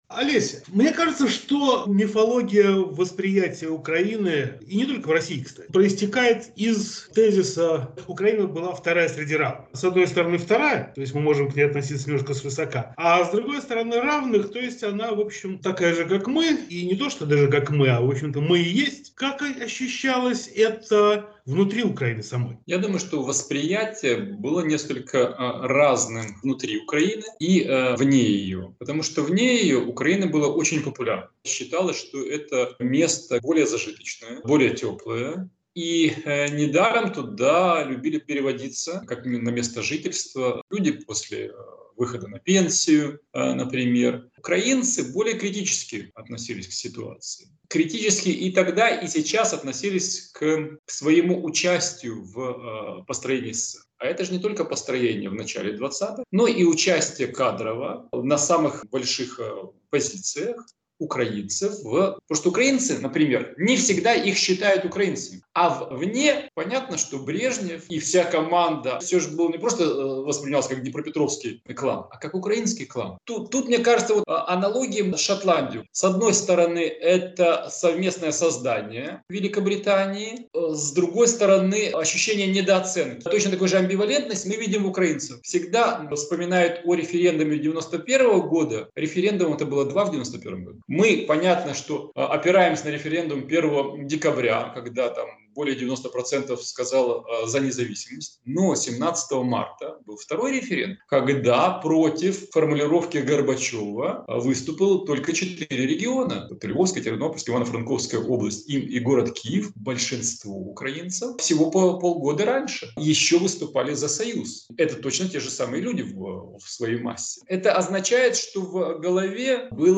Вторая среди равных – о советском и постсоветском феномене Украины, без которой был немыслим как сам Советский Союз, так и его распад, говорим с Гостем недели, главой украинского Центра исследований...